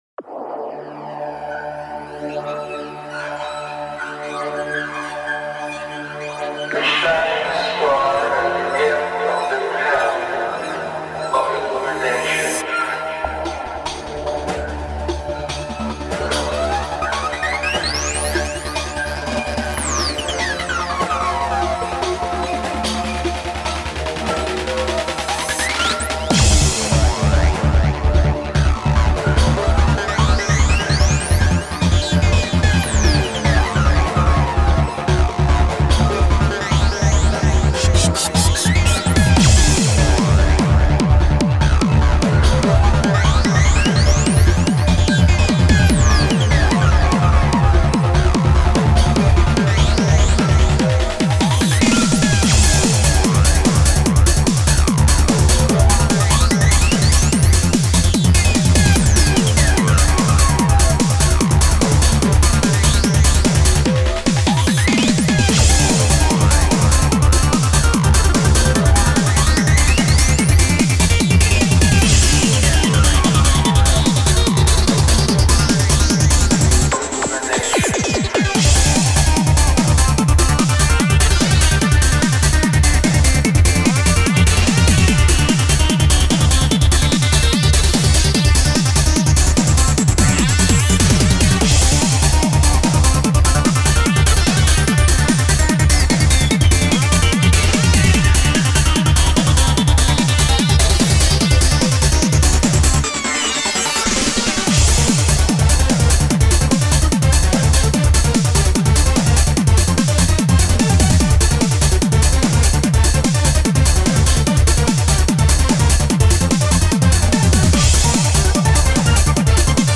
Транс музыка